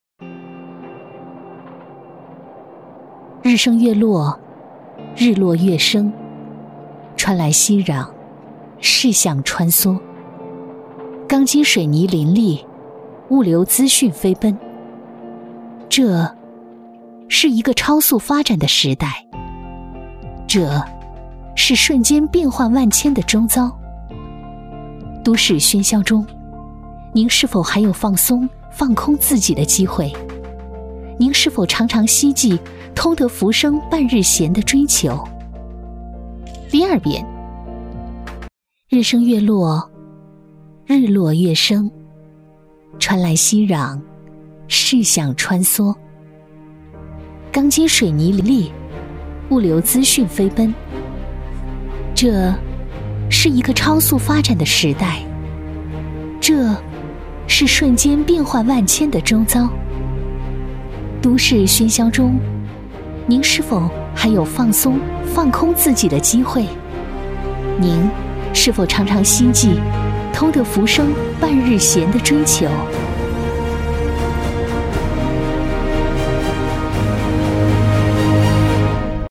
• 女S114 国语 女声 宣传片 玉渊潭城市宣传片 沉稳 亲切甜美